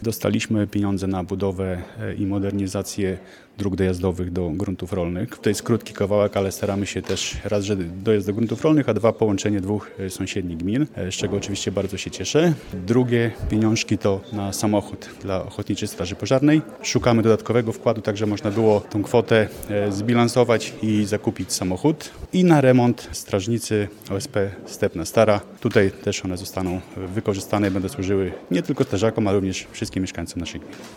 Dzięki otrzymanym środkom możemy udoskonalać i rozwijać nasz region – dodaje wójt gminy Olszewo-Borki – Krzysztof Grala: